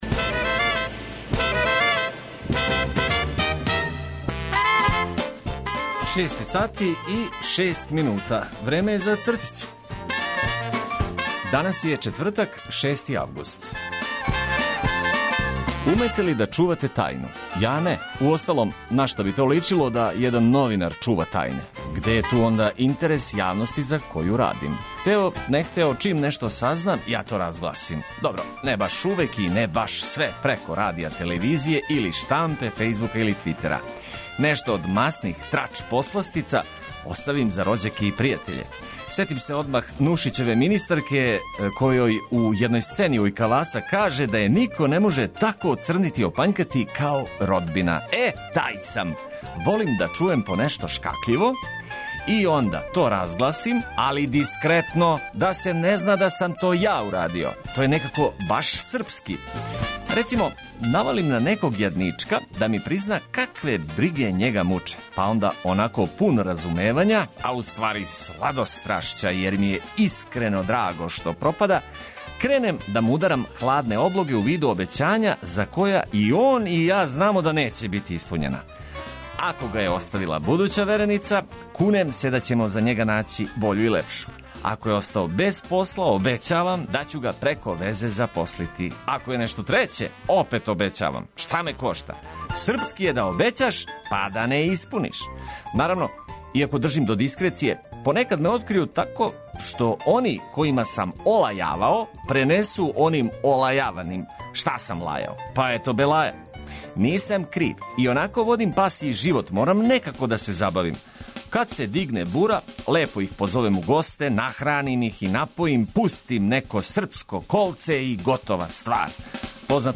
Уредник и водитељ: